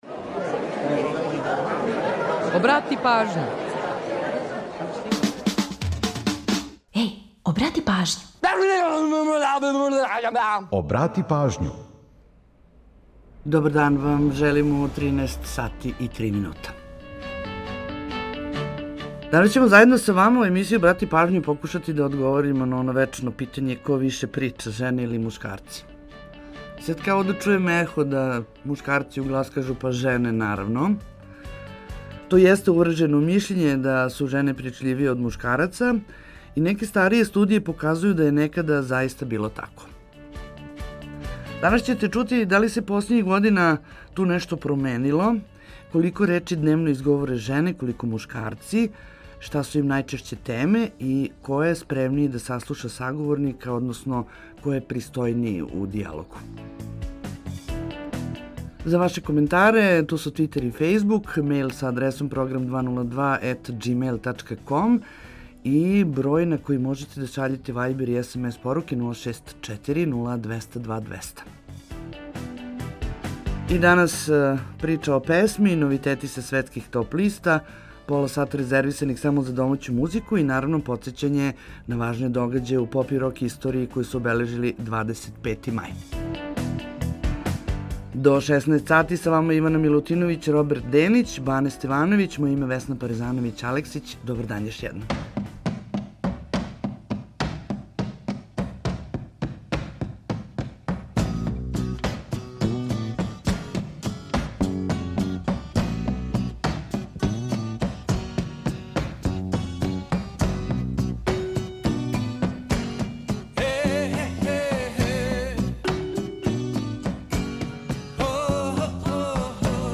Очекује вас још једна прича о песми, новитети са светских топ листа и пола сата резервисаних само за домаћу музику.